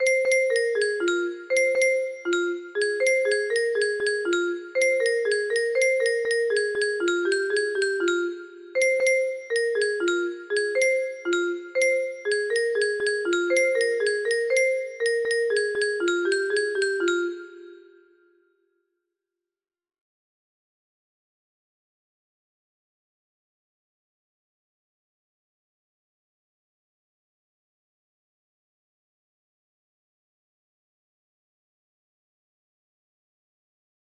Faerie Theme music box melody